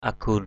/a-ɡ͡ɣun/ (d.) cây cườm thảo, cam thảo đỏ = Abrus precatorius.
agun.mp3